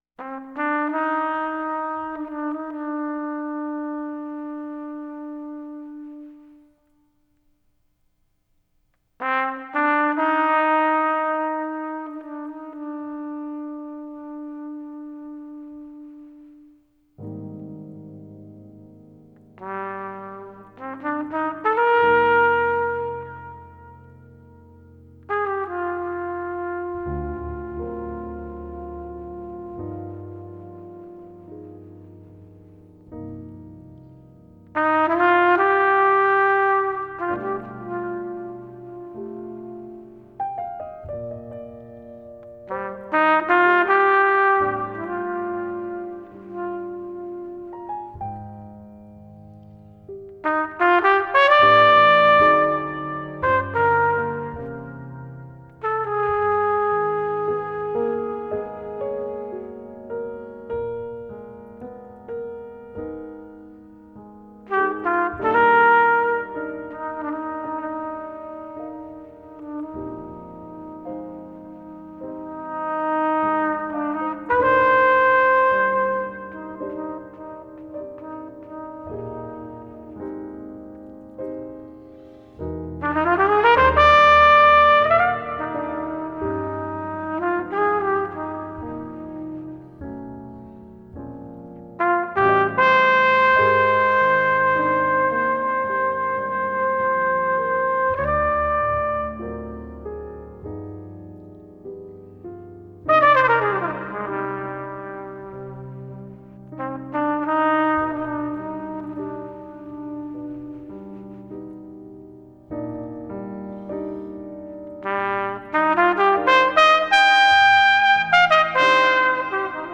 melancholy trumpet